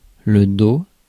Ääntäminen
France: IPA: [do]